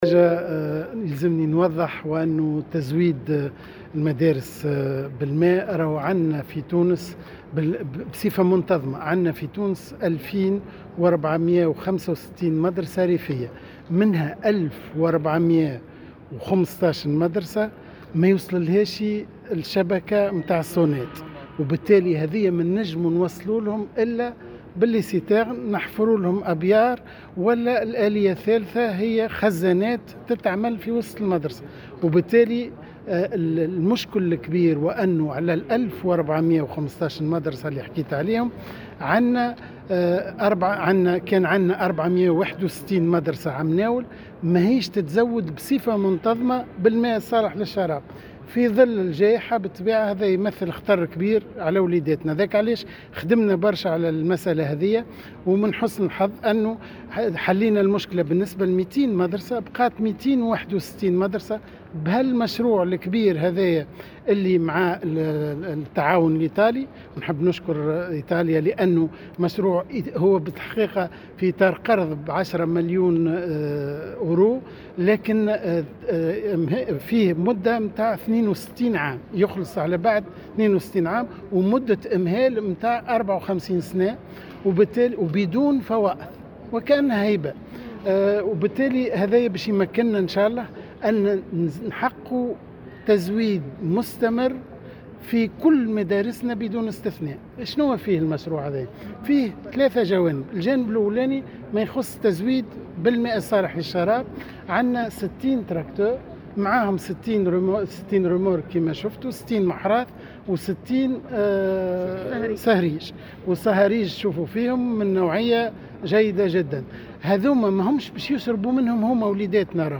وأضاف في تصريح اليوم لمراسل "الجوهرة أف أم" على هامش توزيع معدات وتجهيزات وجرارات على المندوبيات الجهوية للتربية، أنه من جملة 2465 مدرسة ريفية، 1415 مدرسة غير مرتبطة بشبكة المياه الصالحة للشراب (يتم تزوييدها عن طريق الخزانات) منها 461 مدرسة لا يتم تزويدها بصفة منتظمة للماء الصالح للشراب (هذا الرقم تقلّص إلى 261 وذلك بعد حل الإشكال بالنسبة لـ200 مدرسة ريفية).